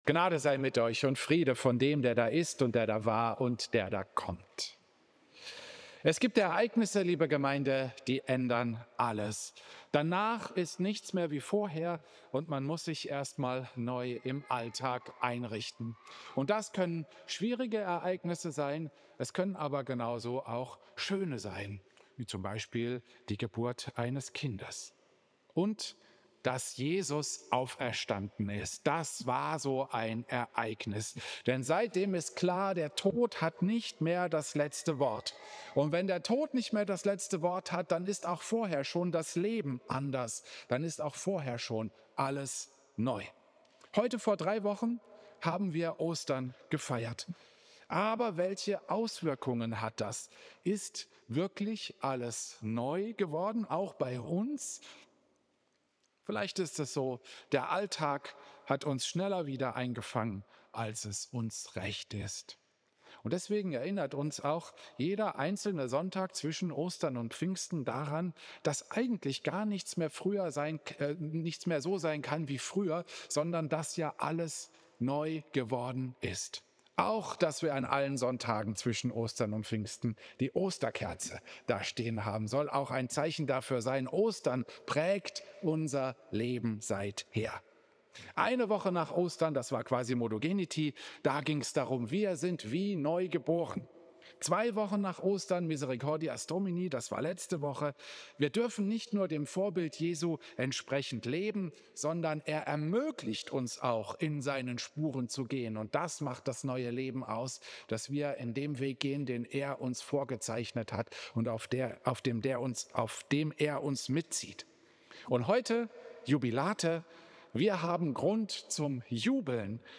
Predigt
Klosterkirche Volkenroda, 26.